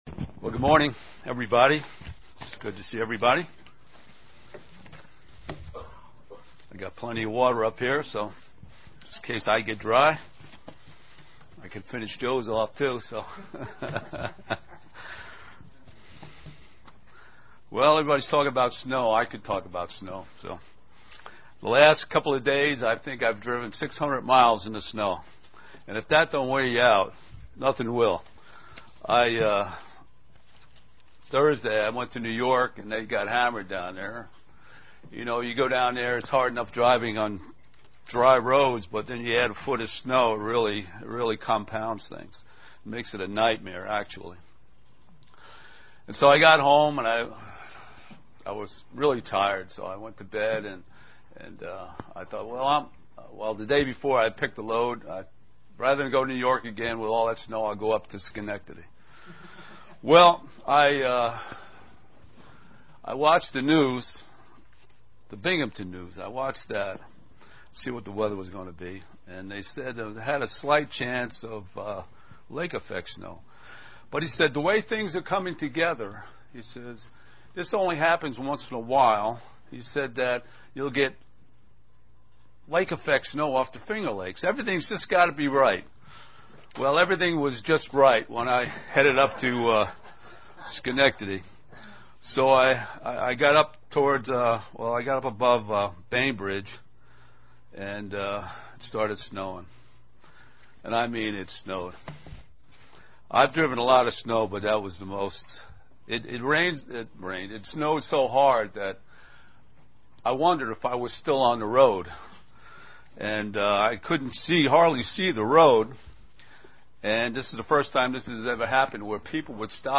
UCG Sermon Studying the bible?
Given in Elmira, NY